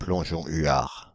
Ääntäminen
Synonyymit plongeon imbrin Ääntäminen France (Île-de-France): IPA: /plɔ̃.ʒɔ̃ y.aʁ/ Haettu sana löytyi näillä lähdekielillä: ranska Käännös Konteksti Substantiivit 1. great northern diver brittienglanti 2. common loon Suku: m .